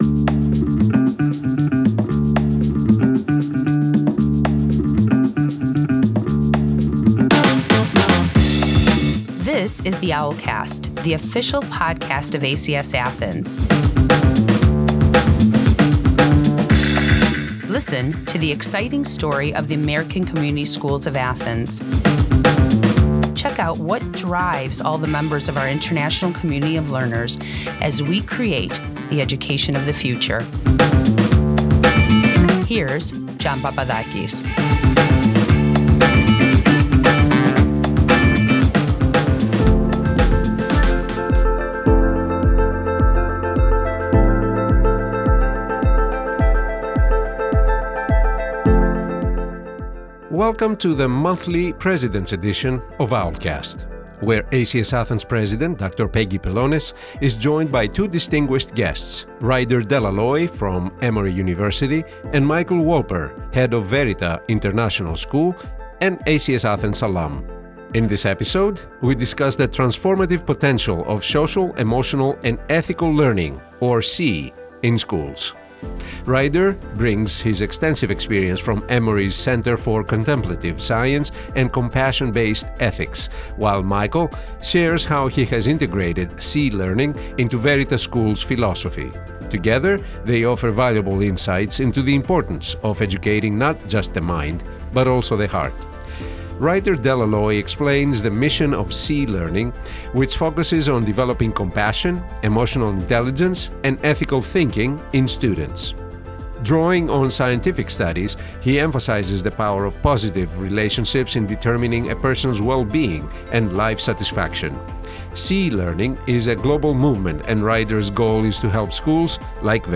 This episode of Owlcast "President's Edition" features a discussion on the importance of Social, Emotional, and Ethical (SEE) learning in schools.